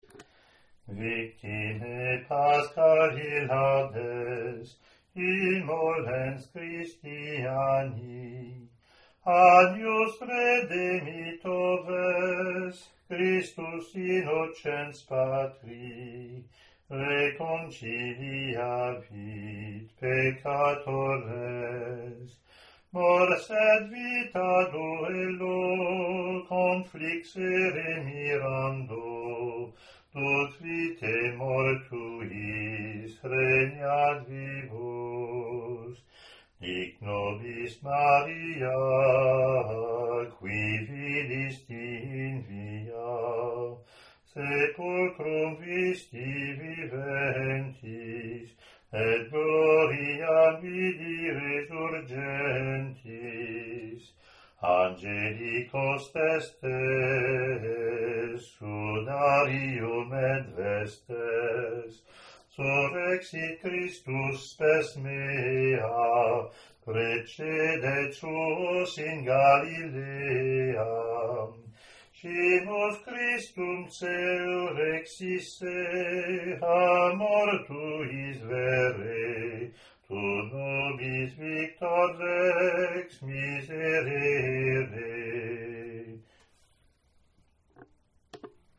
hwes-sequence-gm.mp3